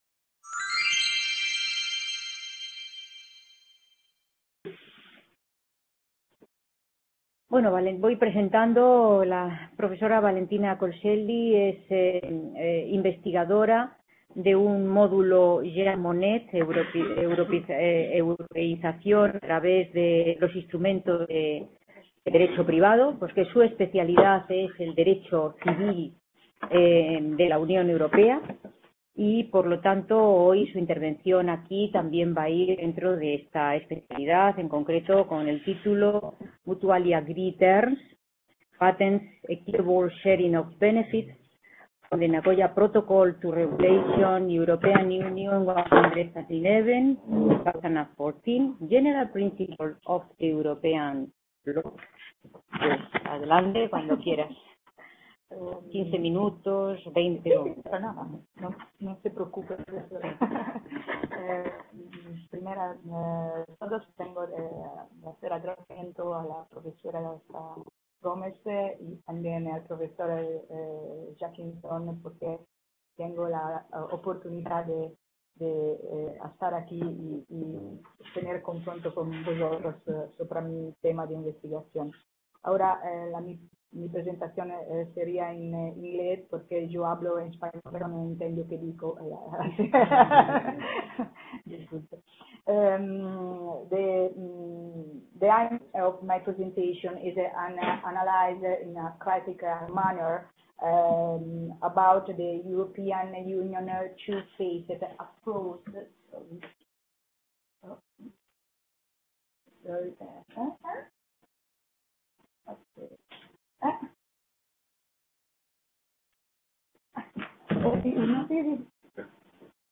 Description C.A. Bruselas - IV Congreso Internacional sobre Unión Europea: nuevos problemas, nuevas soluciones.